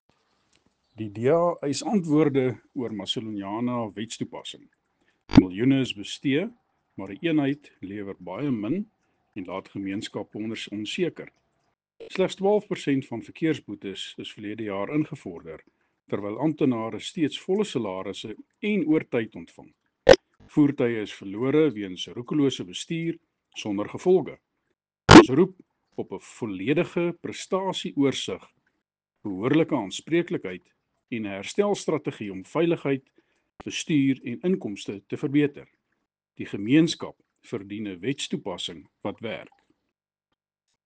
Afrikaans soundbite by Cllr Andre Kruger.